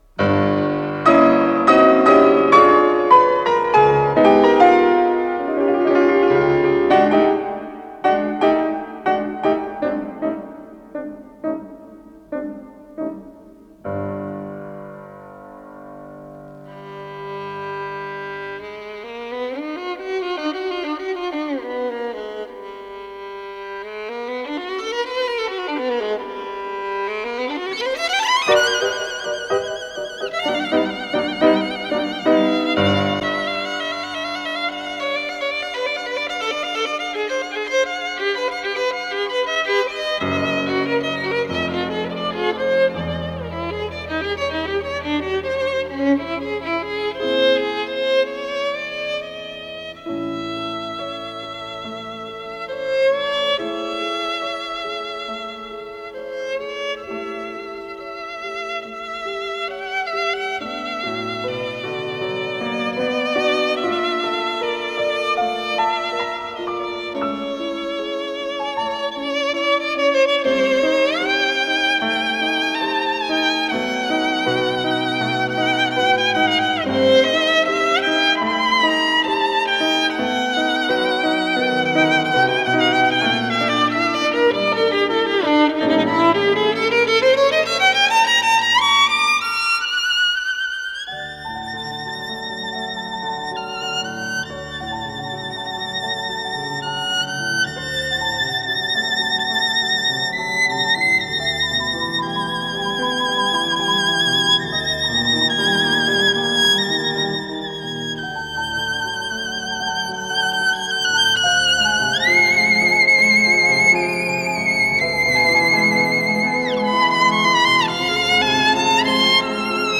скрипка
фортепиано